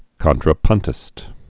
(kŏntrə-pŭntĭst)